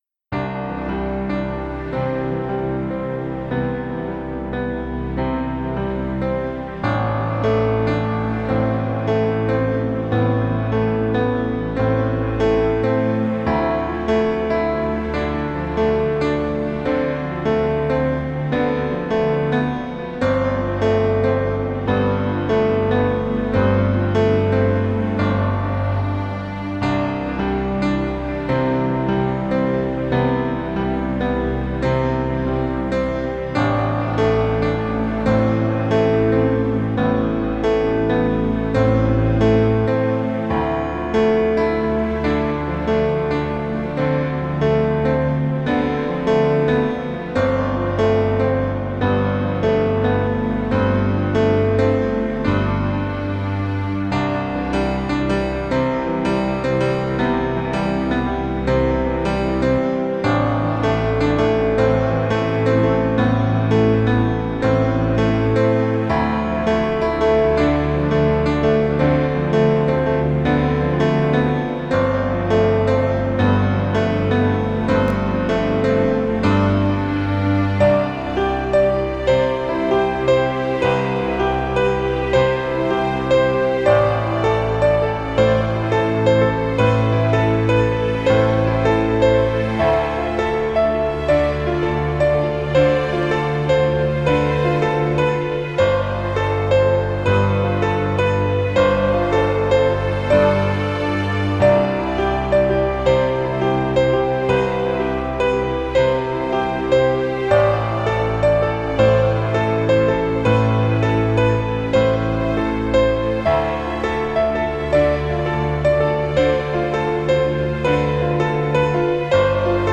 Piano Keys